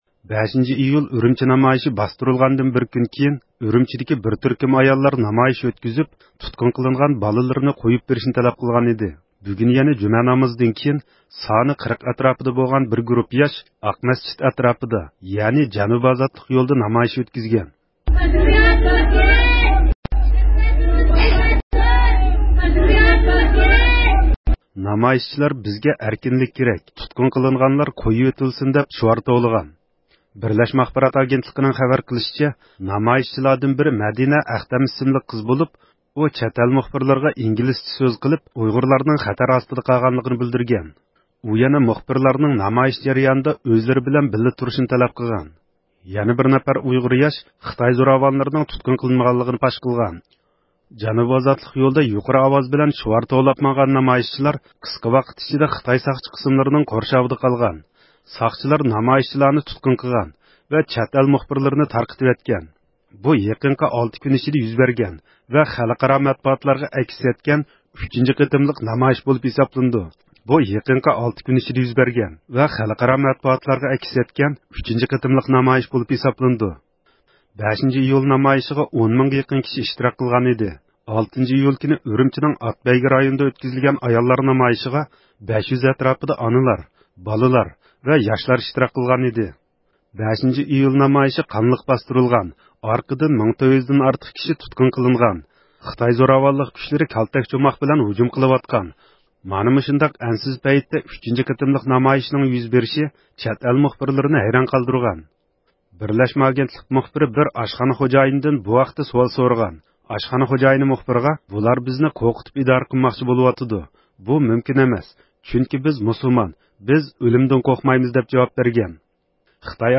دۆڭكۆۋرۈكتە ئولتۇرۇشلۇق بىر ئۇيغۇر ئۇيغۇرلارنىڭ نۆۋەتتىكى كەيپىياتى ھەققىدە توختىلىپ ئۆتتى.